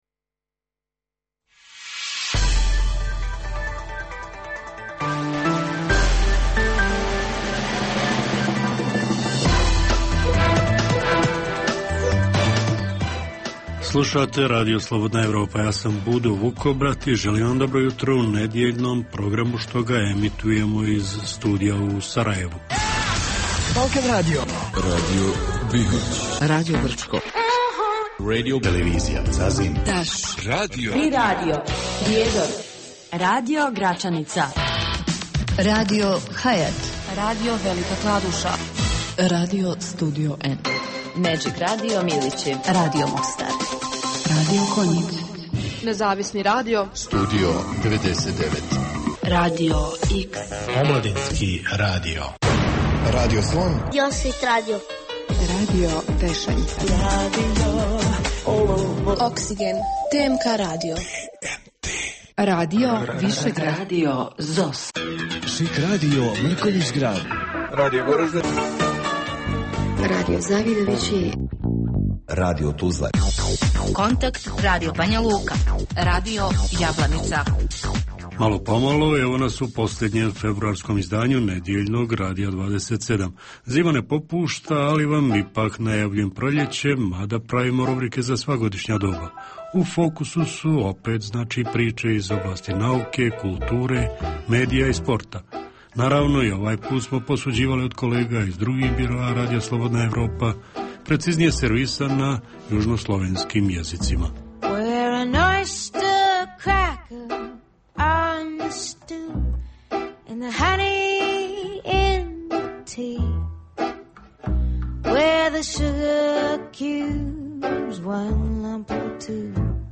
Jutarnji program namijenjen slušaocima u Bosni i Hercegovini. Sadrži intervju, te novosti iz svijeta nauke, medicine, visokih tehnologija, sporta, filma i muzike.